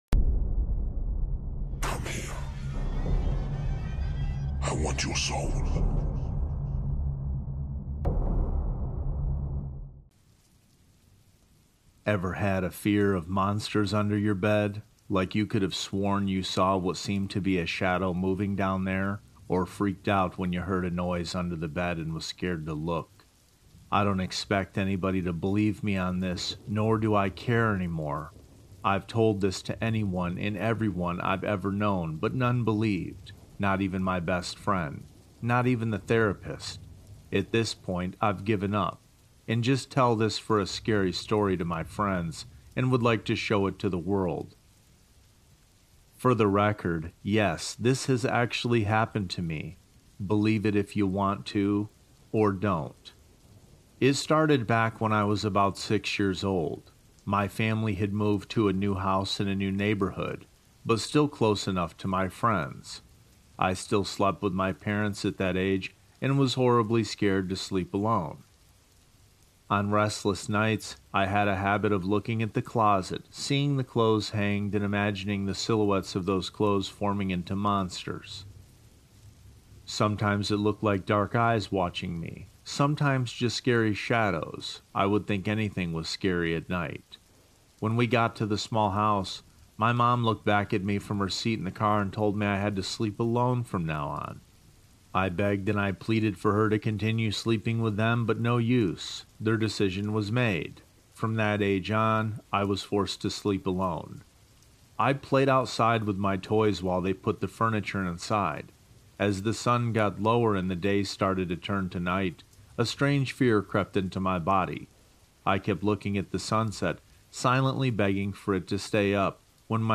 EP 96: 5 Creepypasta Horror Stories | Scary Horror Compilation In The Rain